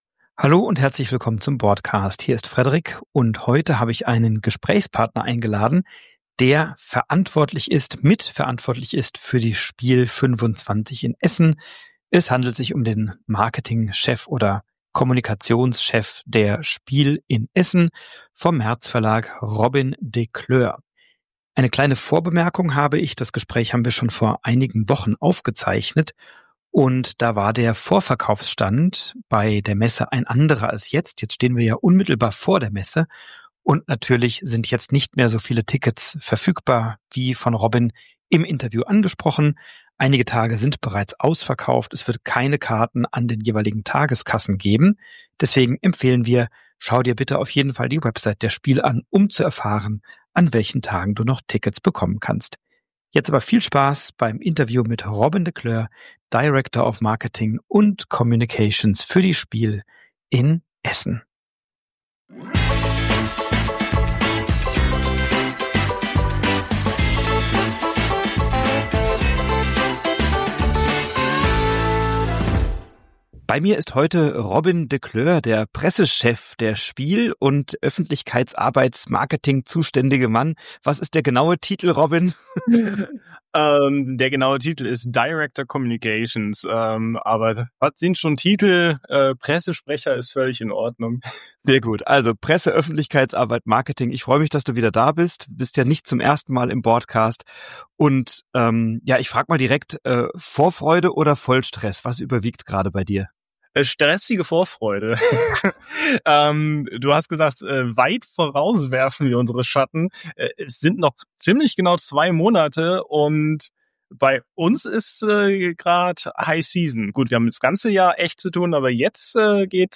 Achtung: Das Gespräch wurde bereits im August aufgezeichnet, es hat nun ein wenig gedauert.